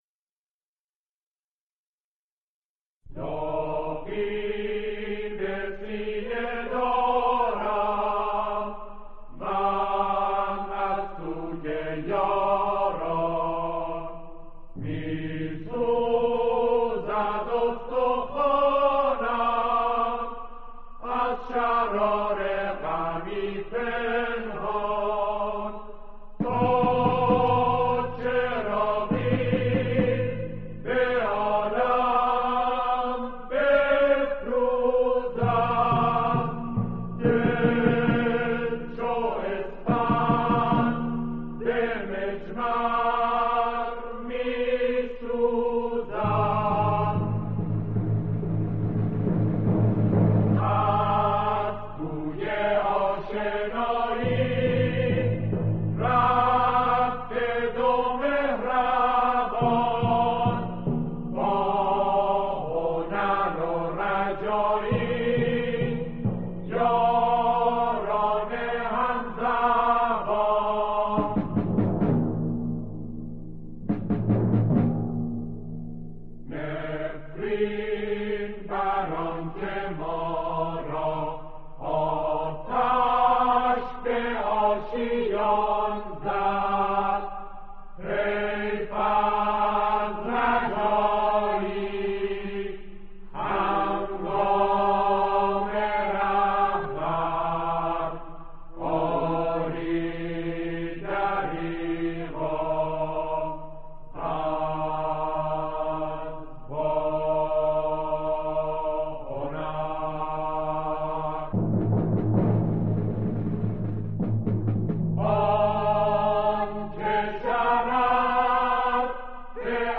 نسخه آکاپلا